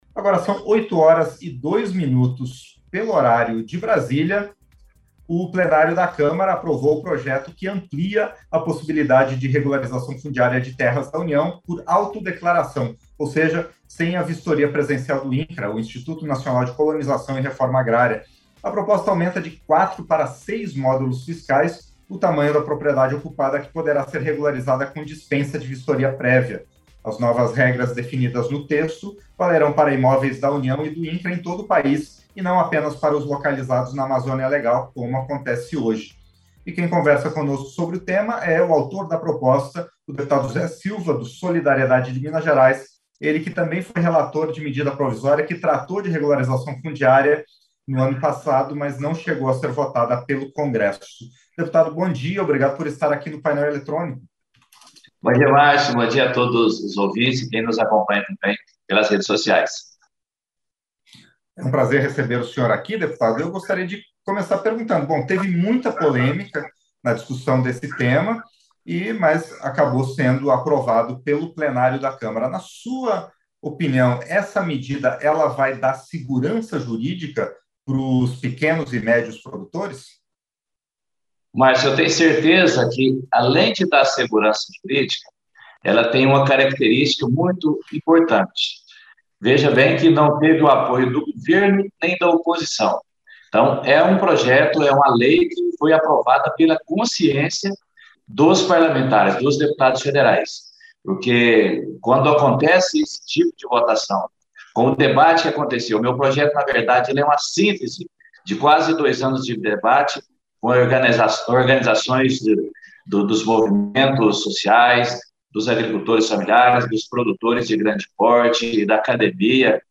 Entrevista - Dep. Zé Silva (SD-MG)